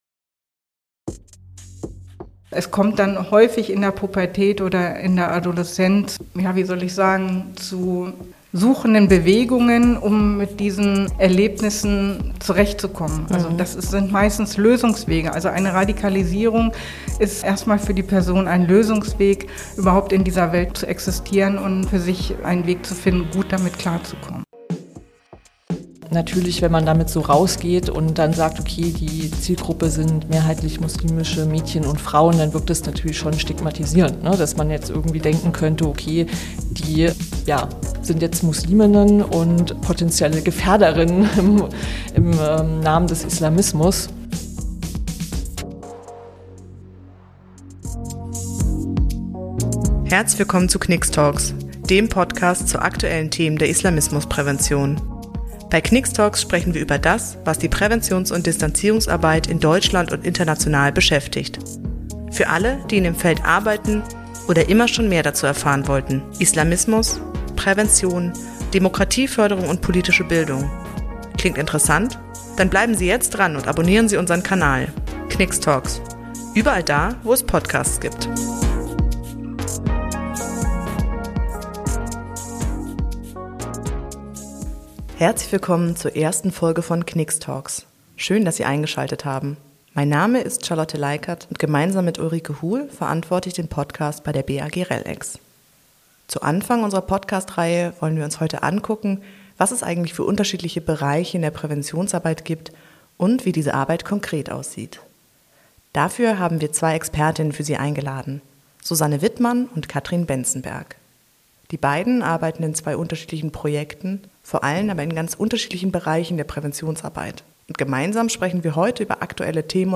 In unserer ersten Folge von KN:IX talks sprechen mit unseren beiden Gästen darüber, wie ihre Arbeit konkret aussieht.